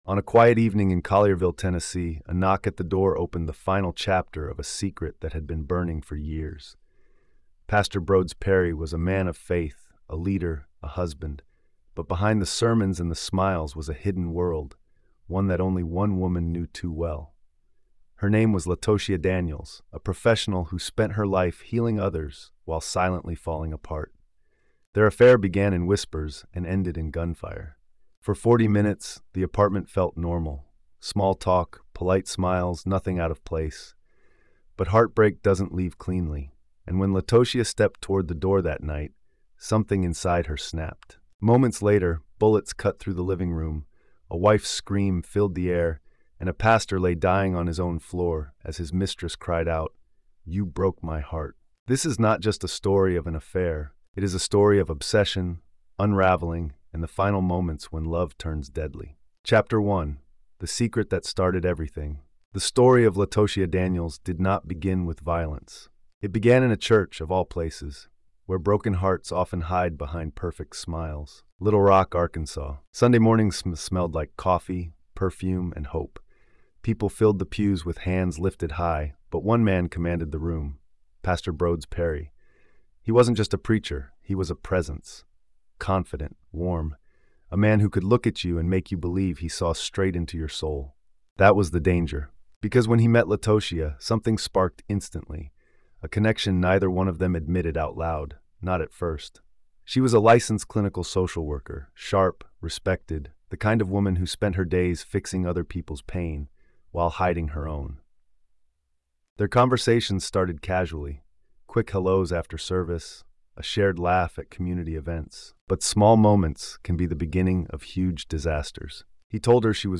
The storytelling then transitions into vivid dramatizations